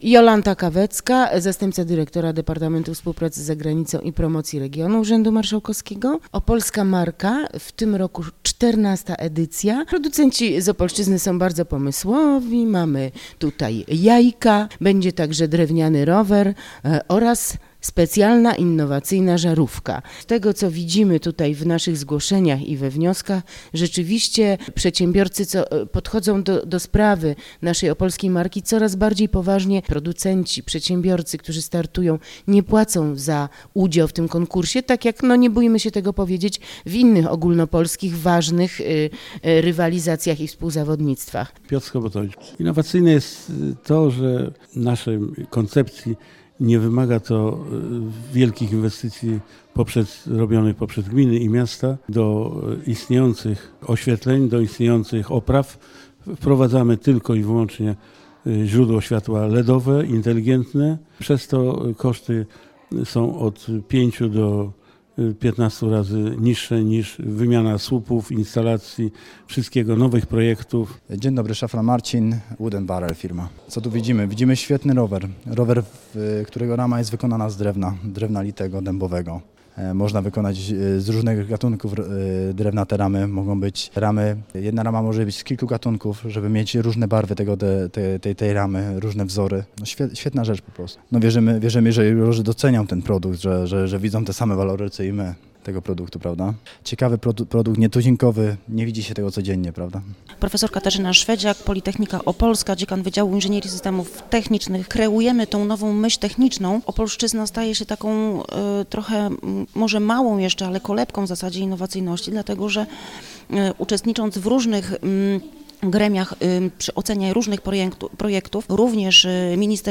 Członkowie jury o tegorocznej edycji „Opolskiej Marki”: